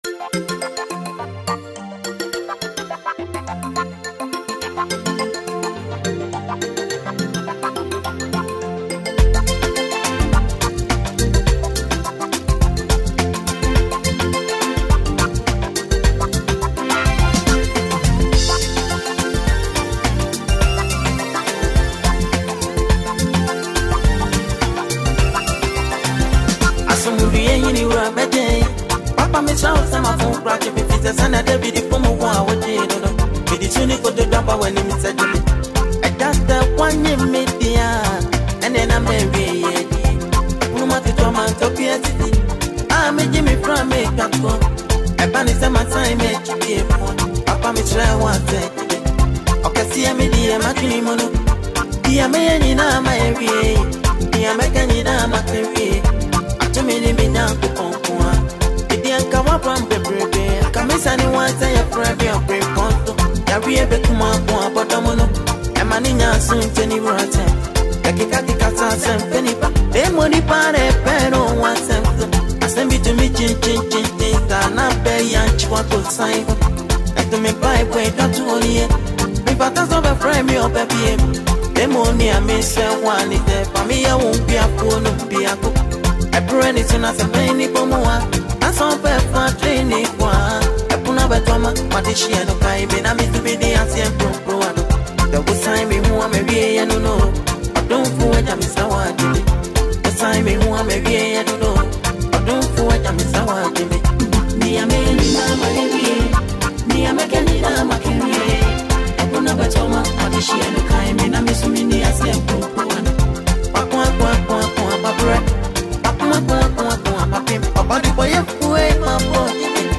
highlife